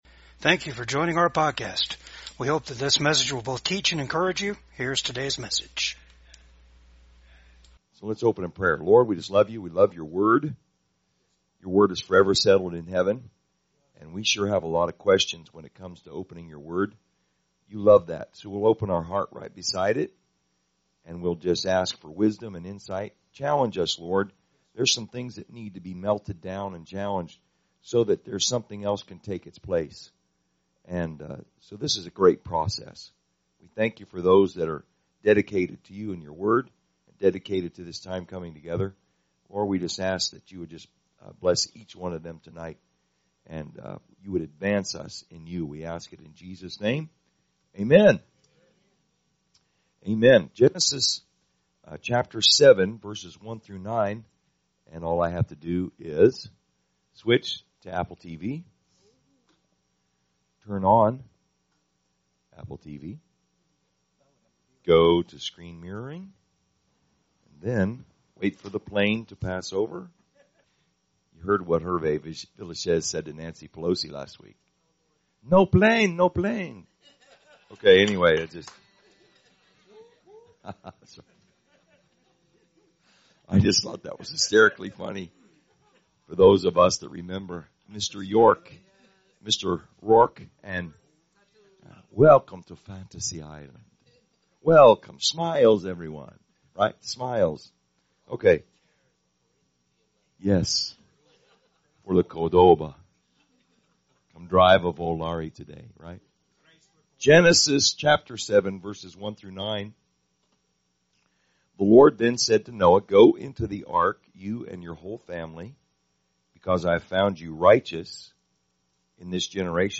PORTIONS OF THIS RECORDING HAVE BEEN REDACTED DUE TO COPYRIGHT CONSTRAINTS.